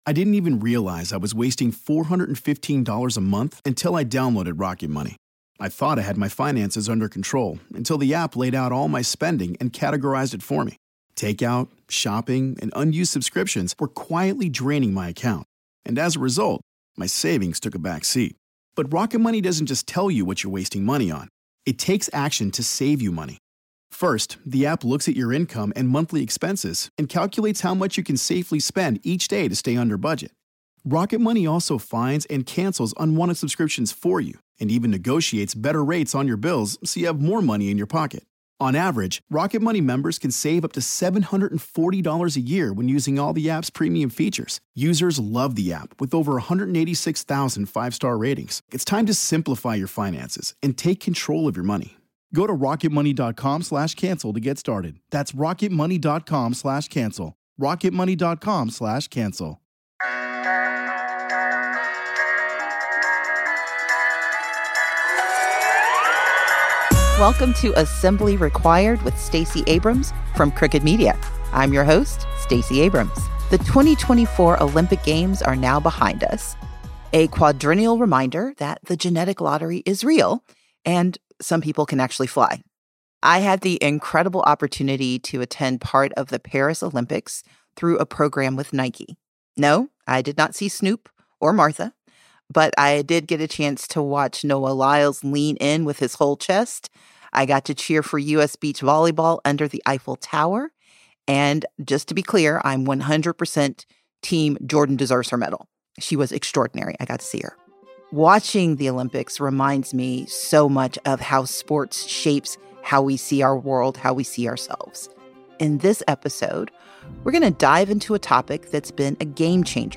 Stacey is joined by WNBA forward Brianna Turner and former NFL defensive back Domonique Foxworth to talk about student athlete compensation, unionizing college teams, and the future of the NCAA.